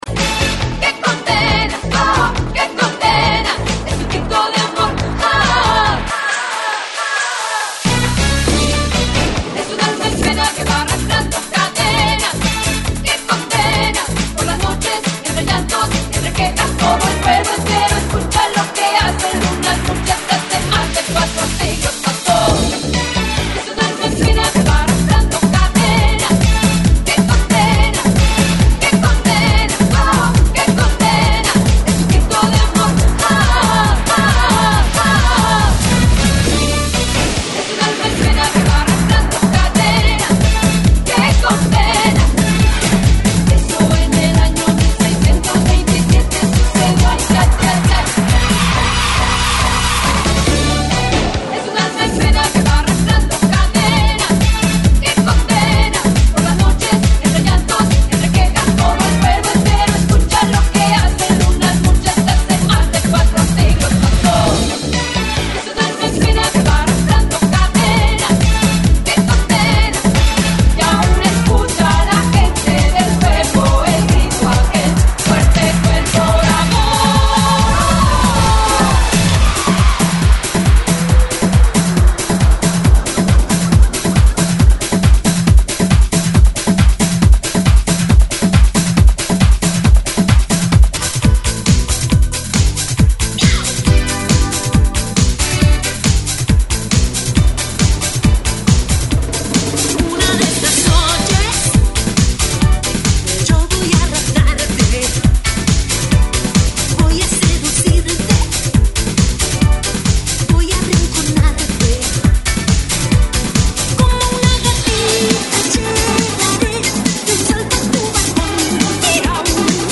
GENERO: RECUERDOS ESPAÑOL 80S 90S
AEROBICS (STEP-HILOW)